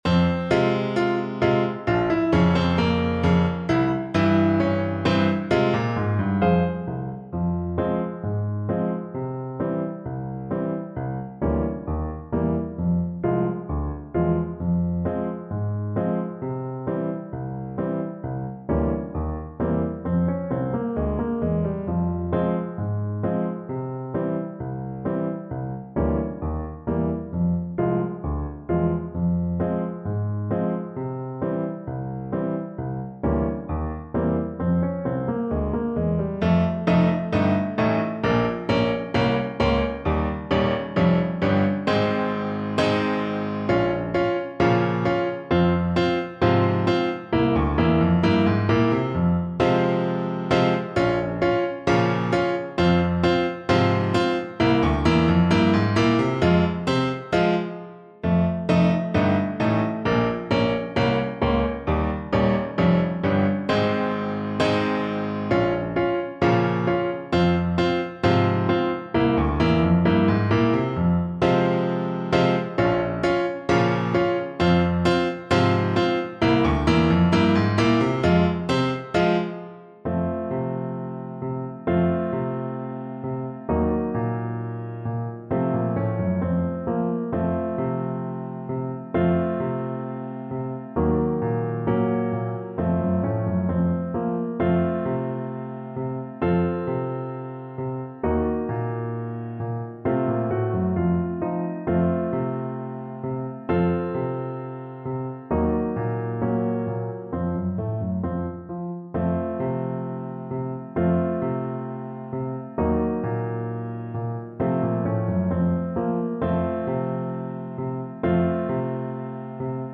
World Europe Greece Hasapiko Grigoro
Alto Saxophone
2/4 (View more 2/4 Music)
F minor (Sounding Pitch) D minor (Alto Saxophone in Eb) (View more F minor Music for Saxophone )
Traditional (View more Traditional Saxophone Music)
hasapiko_grigoro_ASAX_kar3.mp3